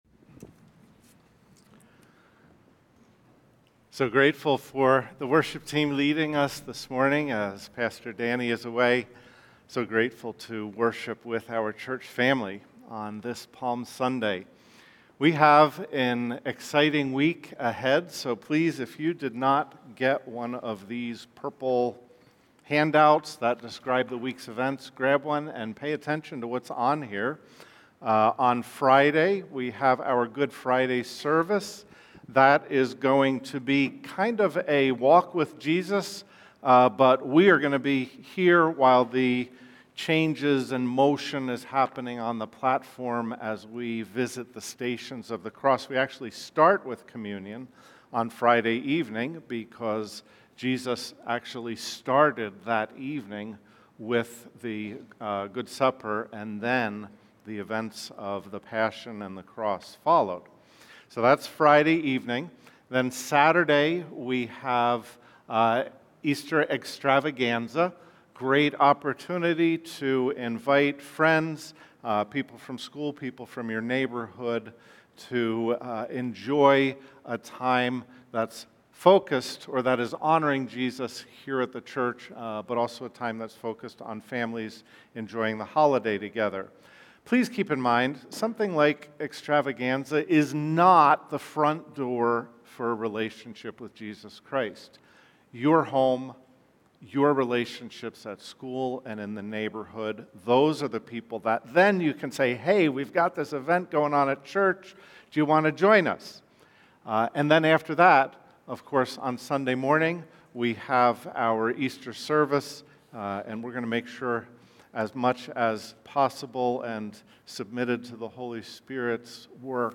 Cary Alliance Church sermons